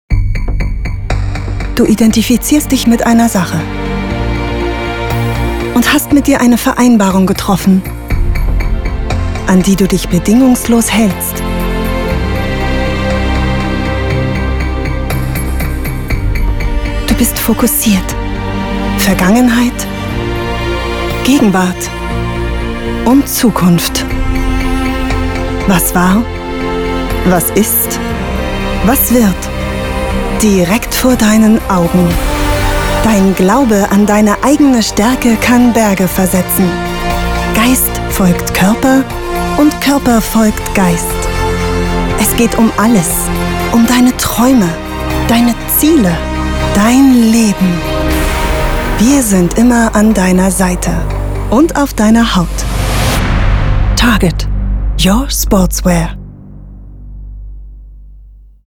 Sprecherin in Berlin, klare und warme Stimme, Mezzosopran, spricht Werbung, Dokumentation, Imagefilme, Audioguides, Hörbuch, Hörspiel, E-Learning, Games, Erklärfilm, Voice over, Telefonansage
Sprechprobe: Sonstiges (Muttersprache):